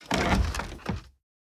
Doors Gates and Chests
Chest Open 1.wav